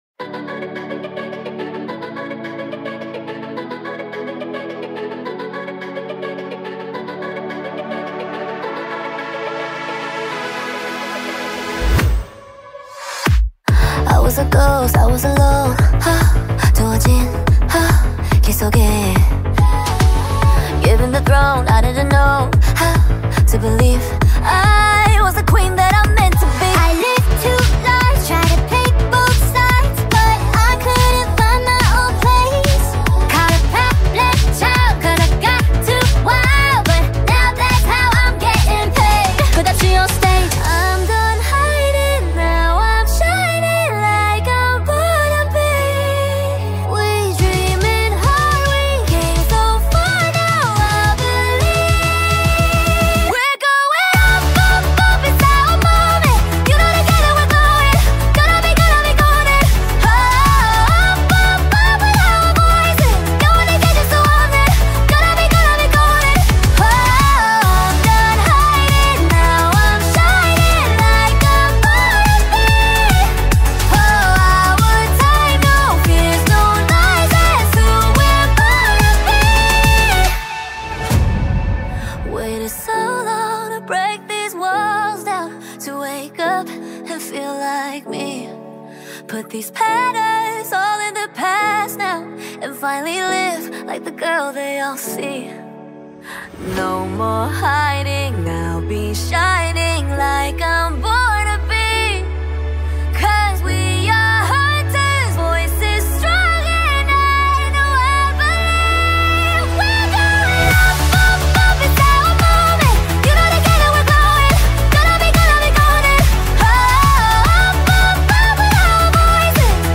با ریتمی تند و دلنشین